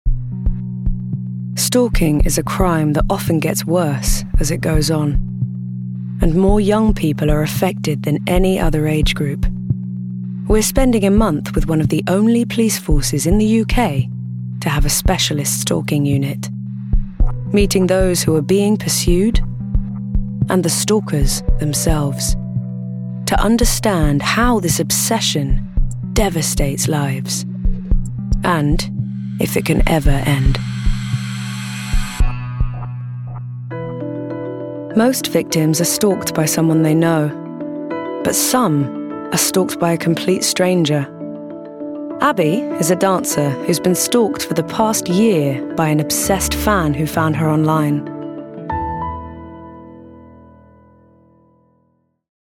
***NEW ARTIST*** | 20s-30s | Transatlantic, Genuine & Charismatic